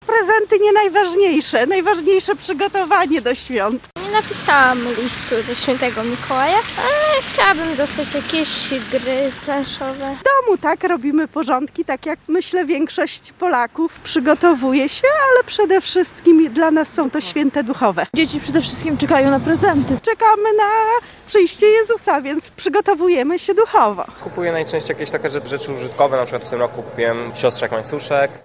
Łomża: Sonda – Czy mieszkańcy mają już gotowe prezenty na święta?
Z mikrofonem Radia Nadzieja pytaliśmy mieszkańców Łomży, czy mają już gotowe świąteczne upominki.